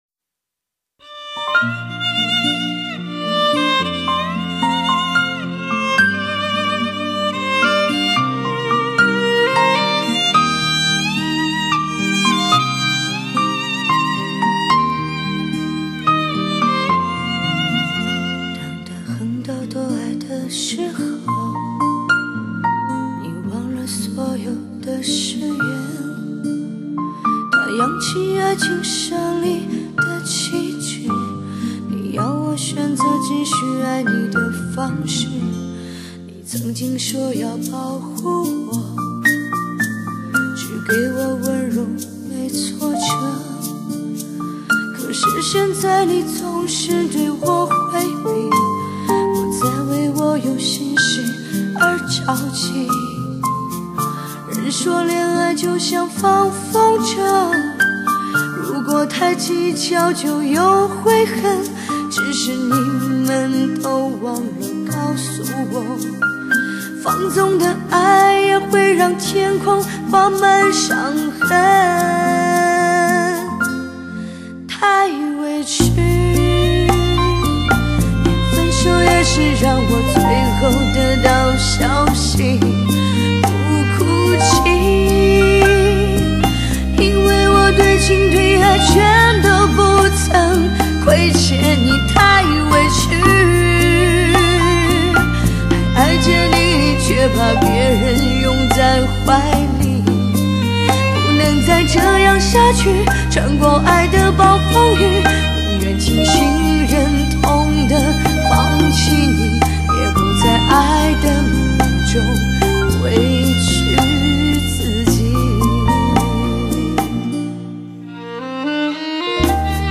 当听到这张发烧的歌曲时，不同音色的人声让你耳目一新，陶醉在五彩韵色中。
磁性迷人歌喉，一声声，一首首，如同一个动人的传说让人产生遐想和幻觉，久违了的记忆，